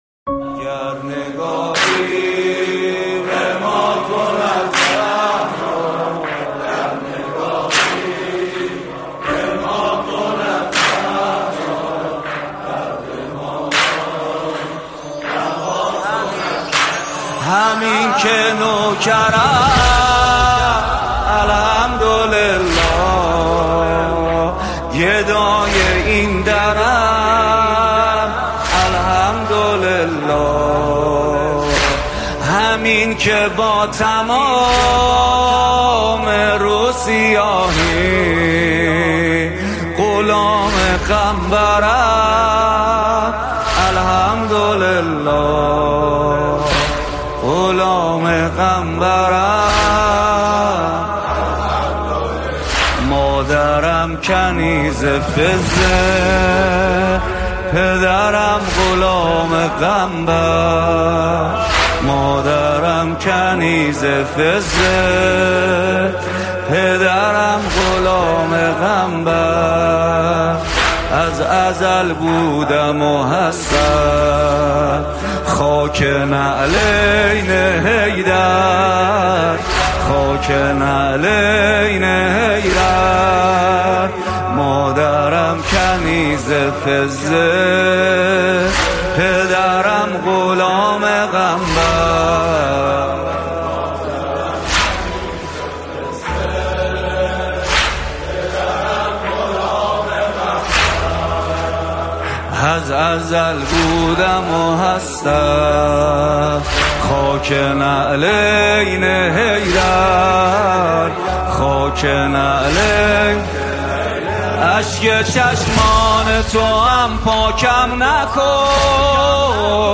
نوحه‌های فاطمی+ صوت